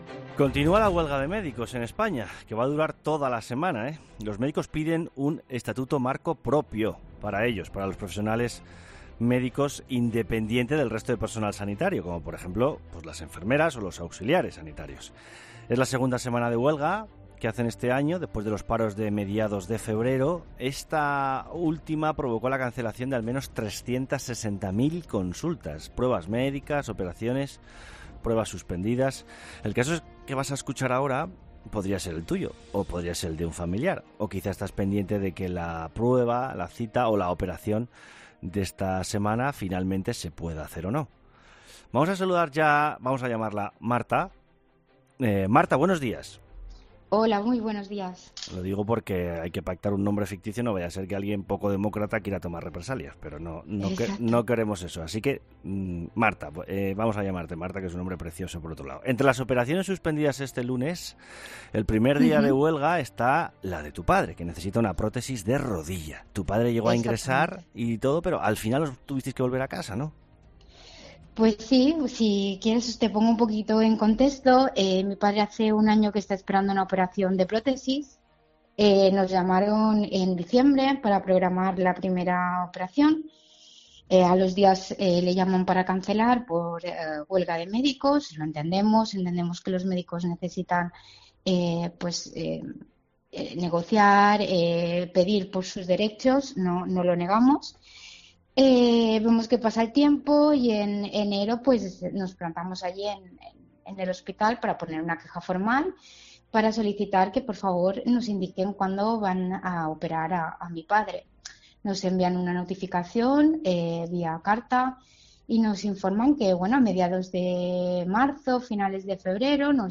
La entrevista de las 9H